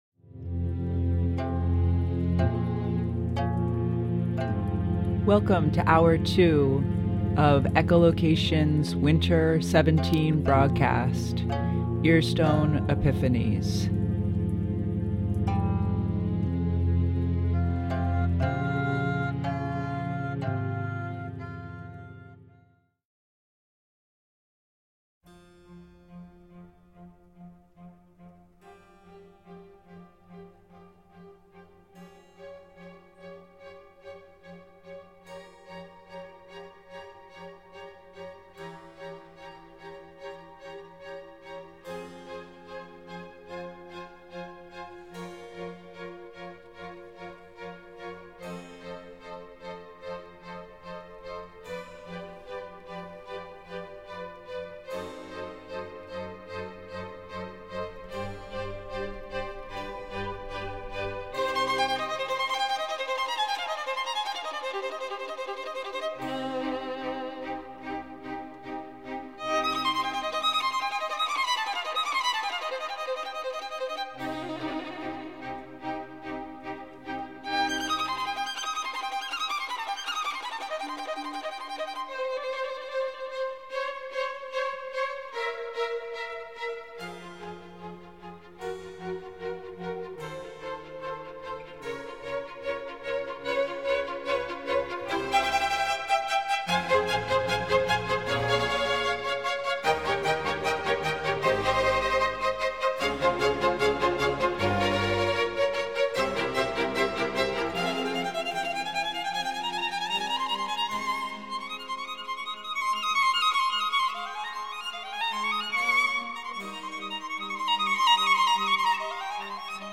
We’ll learn about strange and wondrous things that can be learned by gazing deeply into a fish’s ear. Then, we’ll dive into BioMusic, exploring compositions that incorporate wildlife sounds (we might even get to listen to some singing fish!). This broadcast will include regular segments Echolocator Profile, News from the Sounding Board, and a Winter sound walk.